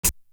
Closed Hats
Big Twins Hat.wav